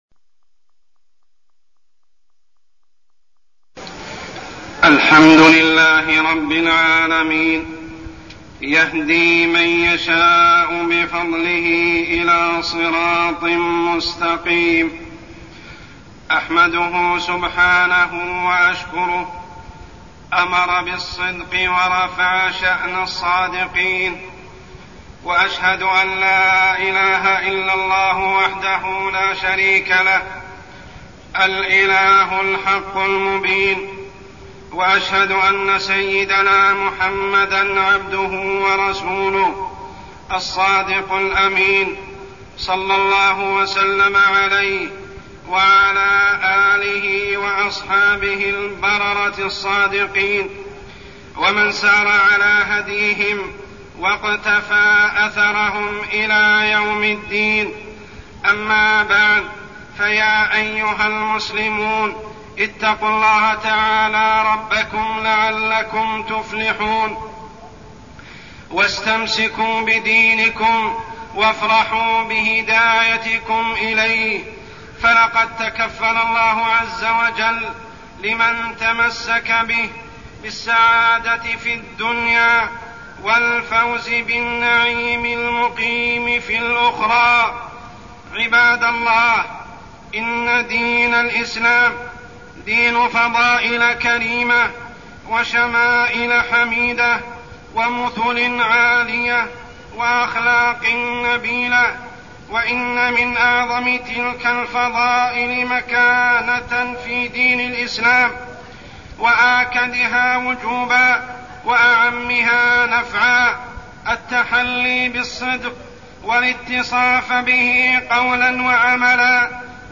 تاريخ النشر ٣ جمادى الآخرة ١٤١٦ هـ المكان: المسجد الحرام الشيخ: عمر السبيل عمر السبيل التحلي بالصدق